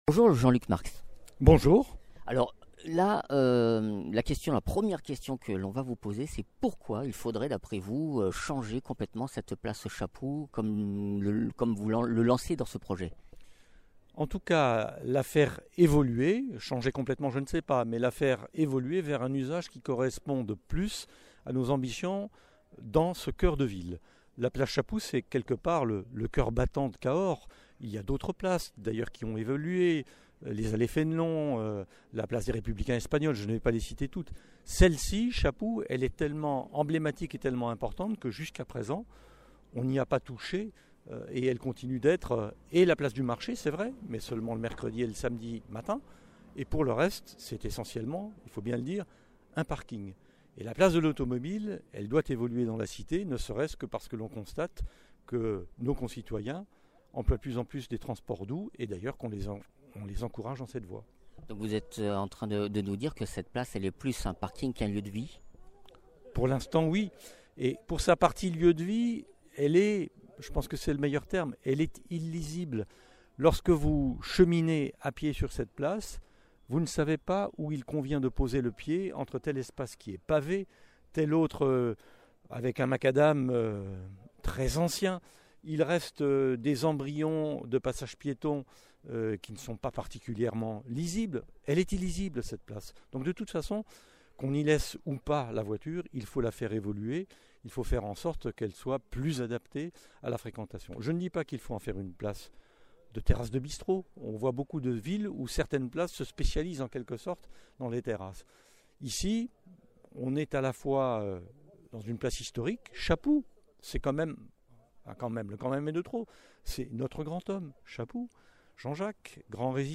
Interviews
Invité(s) : Jean Luc Marx, premier adjoint au maire de Cahors